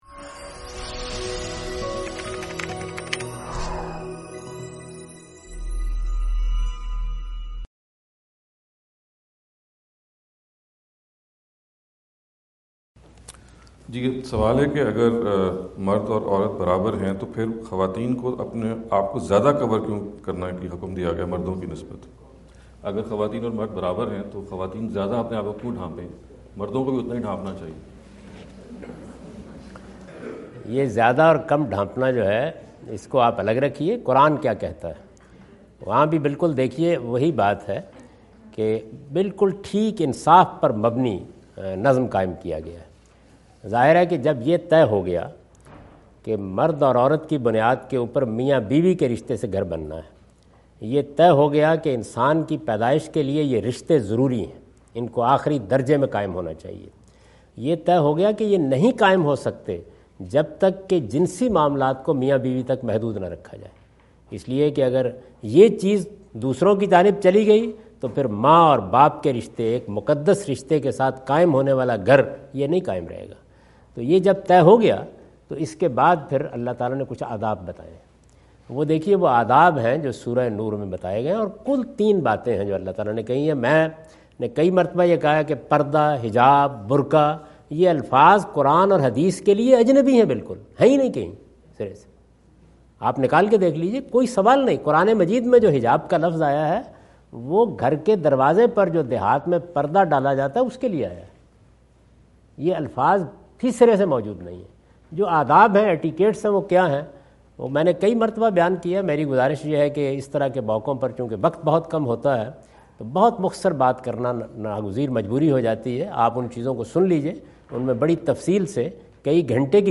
In this video Javed Ahmad Ghamidi answer the question about "Dressing Etiquettes for Men and Women" asked at Lexington USA on October 27,2017.
جاوید احمد غامدی اپنے دورہ امریکہ 2017 کے دوران لیکسنٹن میں "مرد و خواتین کے لباس کے آداب" سے متعلق ایک سوال کا جواب دے رہے ہیں۔